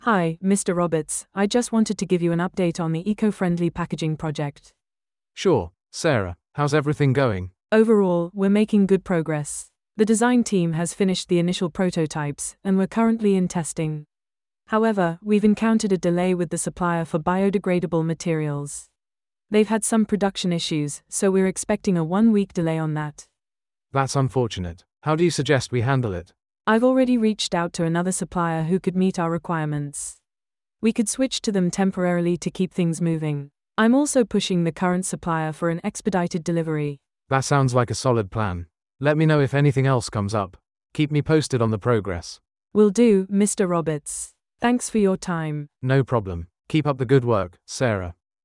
レッスンでは、以下のような会話を聞いた後に、講師と内容についてディスカッションをします。